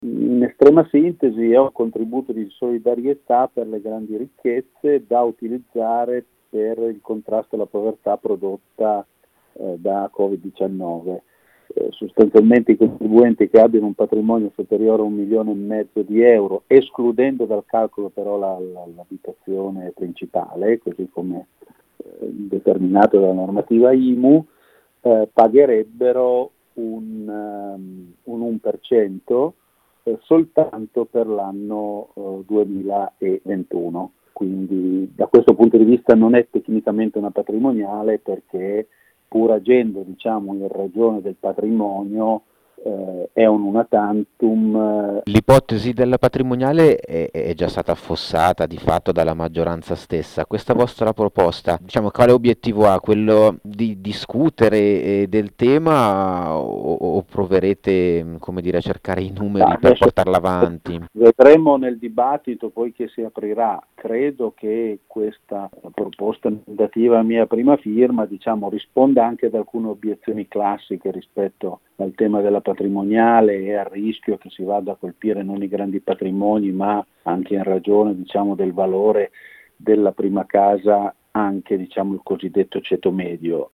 Il primo firmatario è il capogruppo di Liberi e Uguali alla Camera Federico Fornaro: